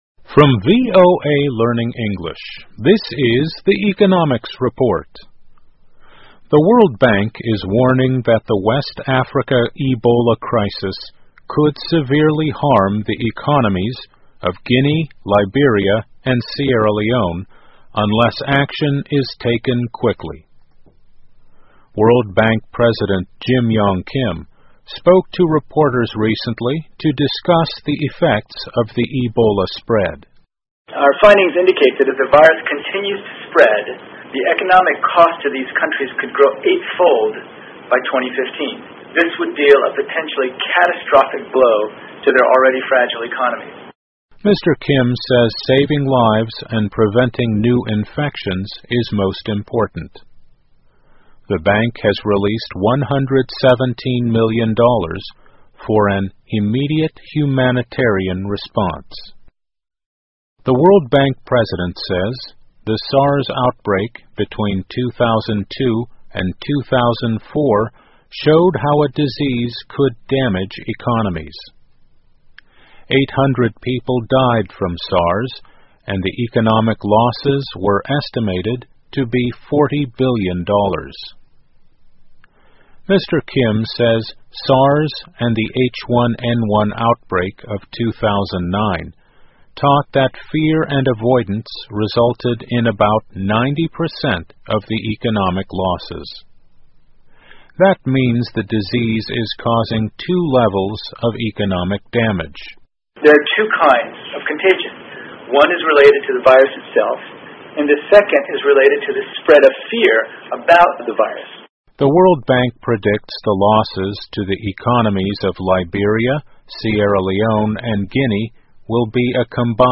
VOA慢速英语2014 埃博拉病毒将造成毁灭性的经济影响 听力文件下载—在线英语听力室